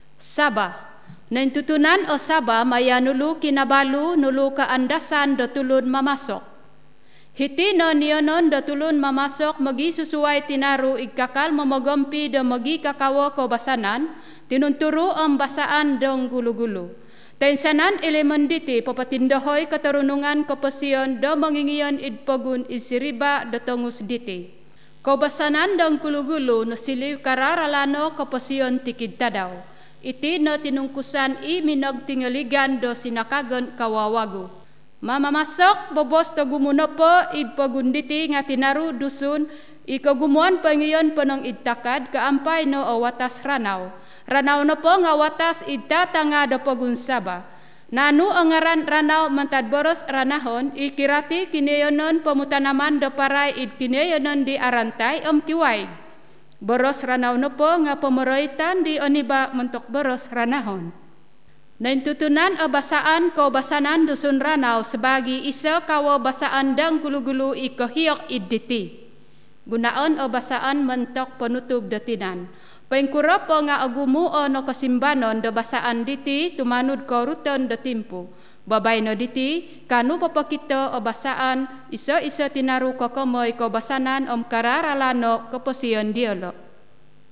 Audio sebutan penutur asli, membantu pelajar membina sebutan yang betul